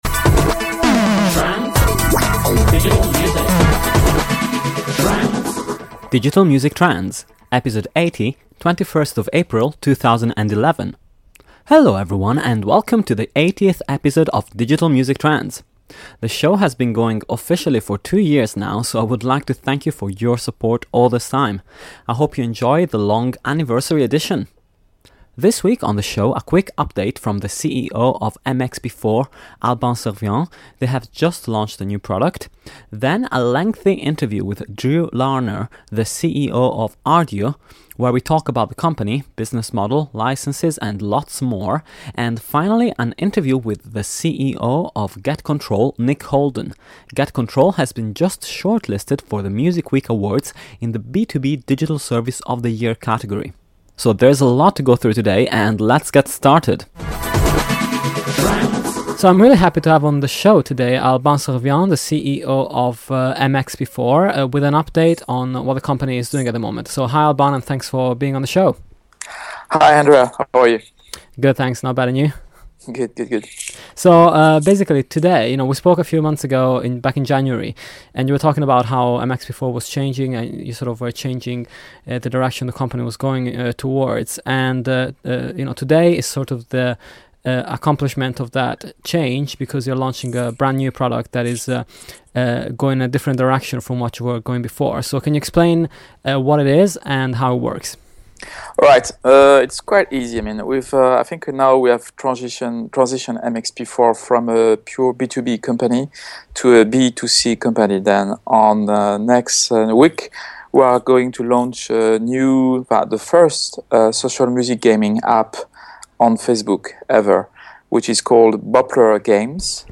The show has been going officially for two years now so I would like to thank you for your support all this time, I hope you enjoy this long anniversary edition – three interviews for you today!